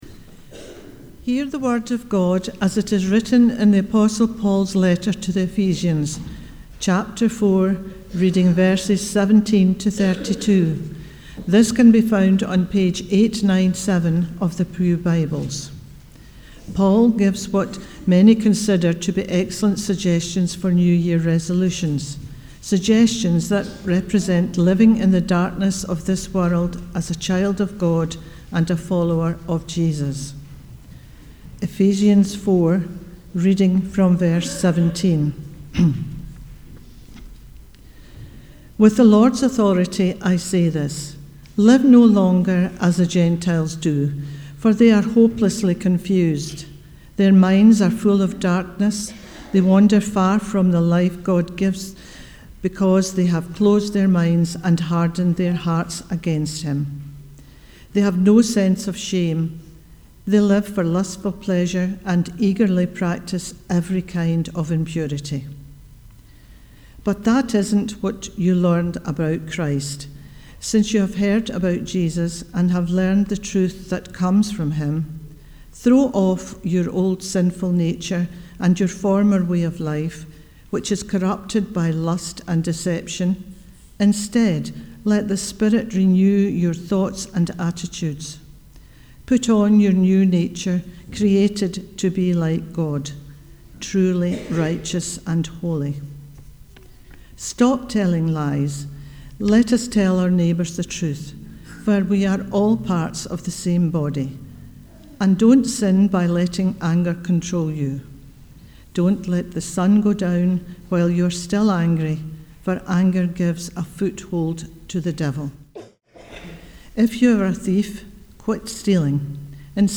The Bible Reading prior to the sermon is Ephesians 4: 17-32